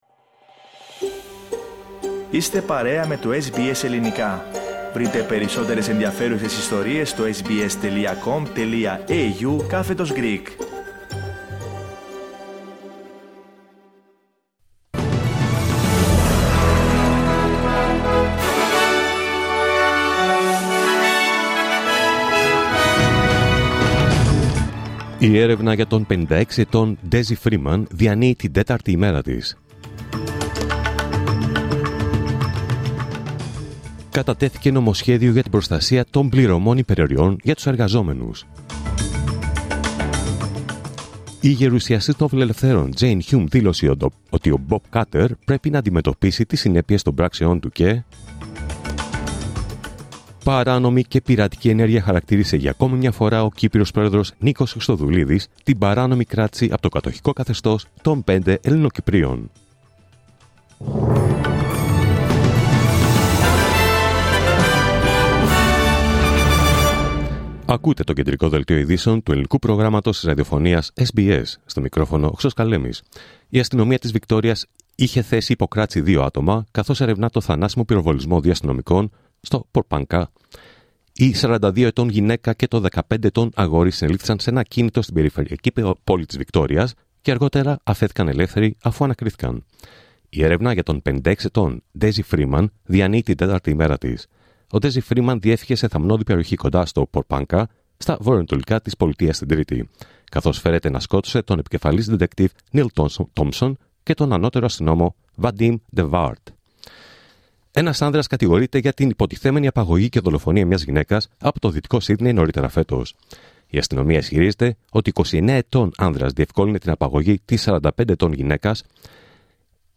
Δελτίο Ειδήσεων Παρασκευή 29 Αυγούστου 2025